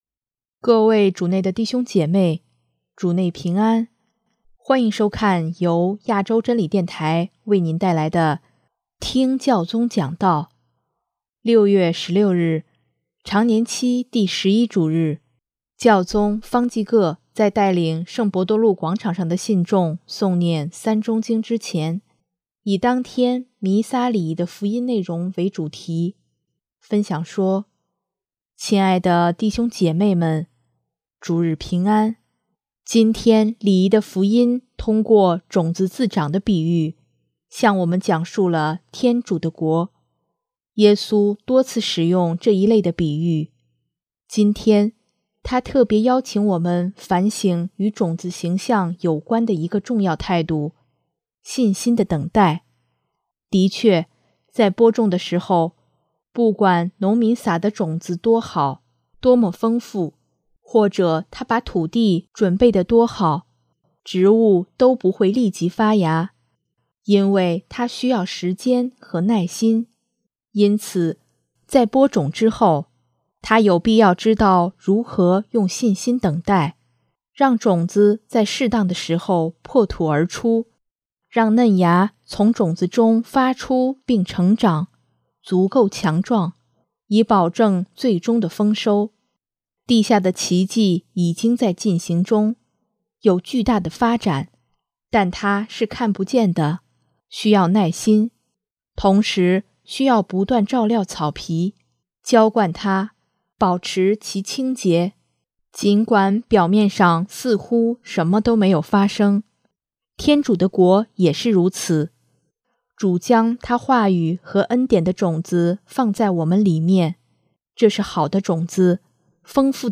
【听教宗讲道】|充满信心地撒播福音的种子
6月16日，常年期第十一主日，教宗方济各在带领圣伯多禄广场上的信众诵念《三钟经》之前，以当天弥撒礼仪的福音内容为主题，分享说：